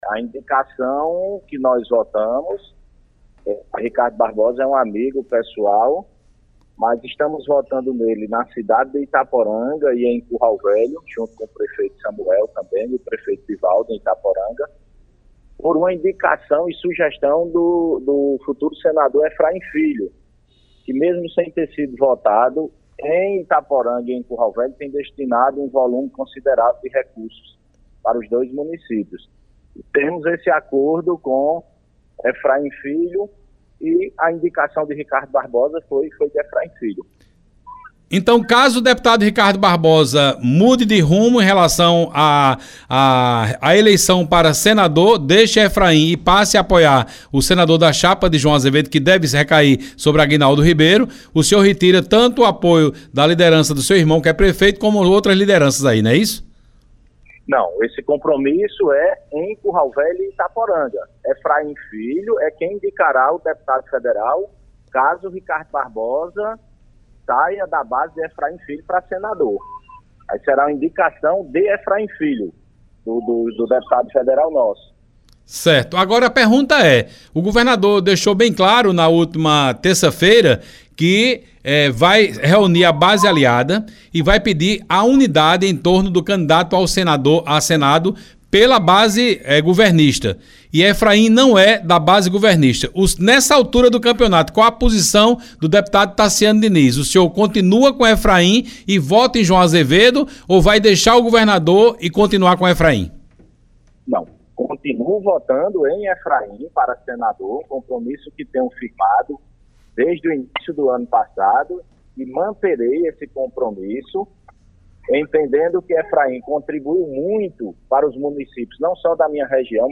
Deputado Taciano Diniz reitera apoio a Efraim em entrevista à Arapuan / Foto: reprodução
Em entrevista ao programa Arapuan Verdade, da Rádio Arapuan FM, nesta quinta-feira (26), o parlamentar disse que não há hipótese dele mudar o voto declarado ao deputado federal Efraim Filho (União), pré-candidato ao Senado, no pleito deste ano.